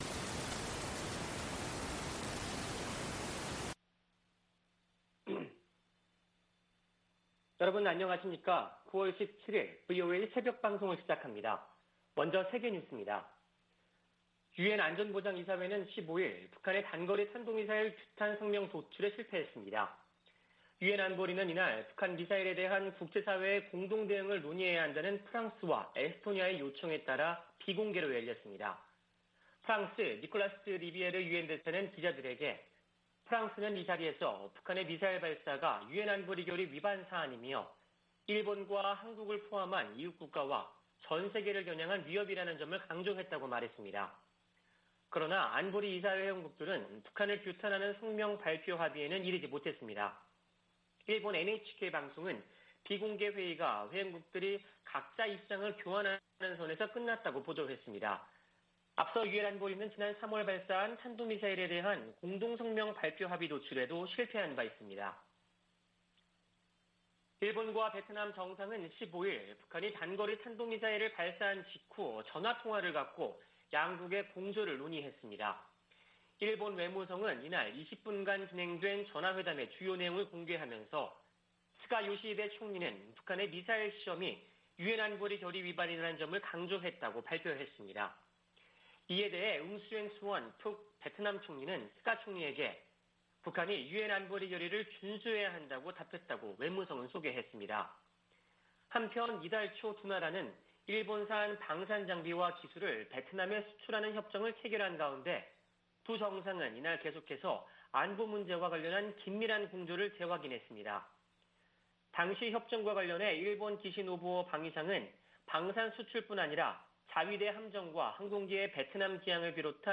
VOA 한국어 '출발 뉴스 쇼', 2021년 9월 17일 방송입니다. 미국 정부가 북한의 탄도미사일 발사를 강하게 규탄했습니다. 유엔 안보리 긴급 회의에서 북한의 최근 단거리 탄도미사일 발사에 대해 논의했습니다. 미국 국무부 국제안보ㆍ비확산 담당 차관보 지명자가 인준받아 임명되면 북한 문제를 최우선 과제로 삼겠다고 밝혔습니다.